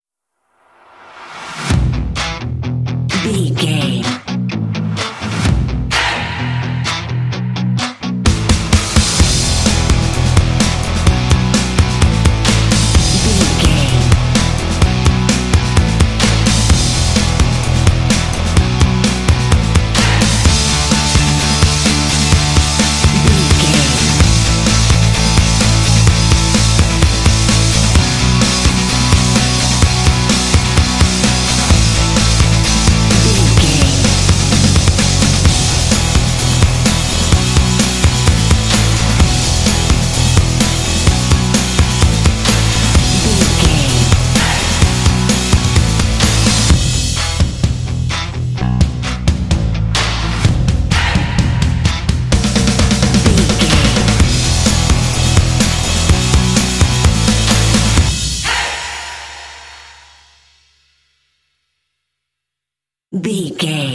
This indie track contains vocal “hey” shots.
Ionian/Major
lively
cheerful
drums
bass guitar
electric guitar
percussion
synth-pop
alternative rock